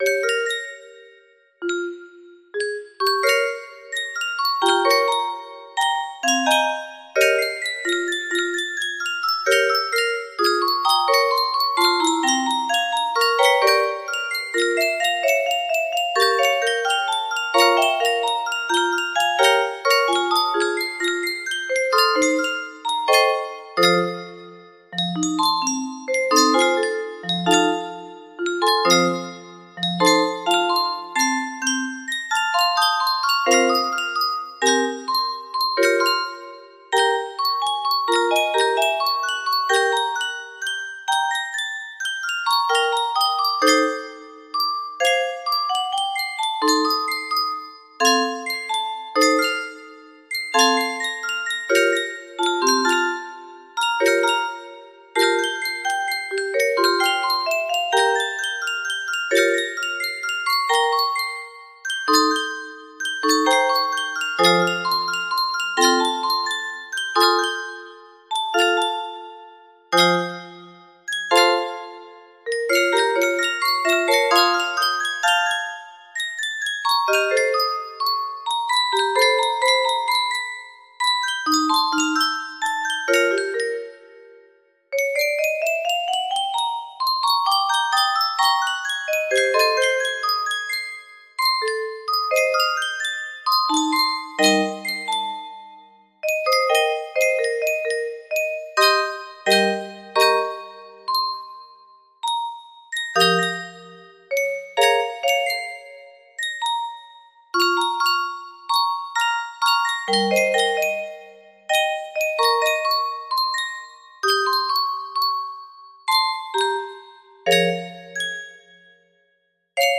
Pursuit music box melody
(Some random jammin composed by me on my midi keyboard)